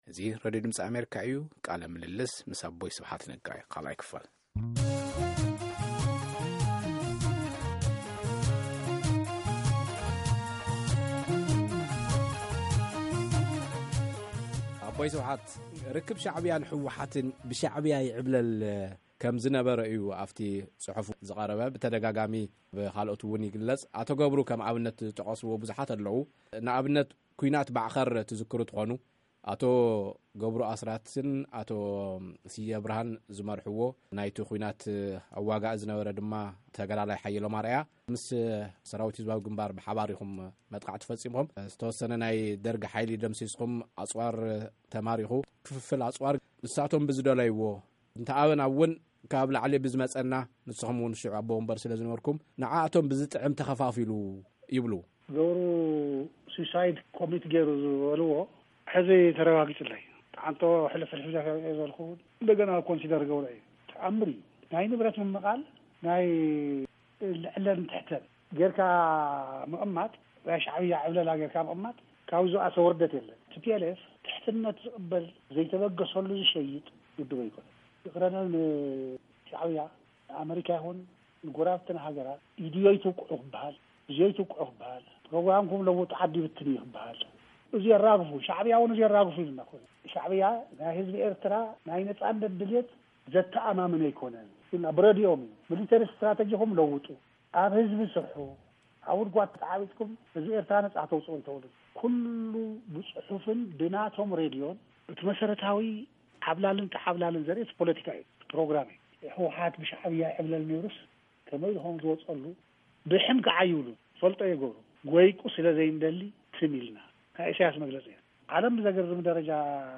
ቃለ ምልልስ ምስ ኣቦይ ስብሓት ነጋ 2 ኽፋል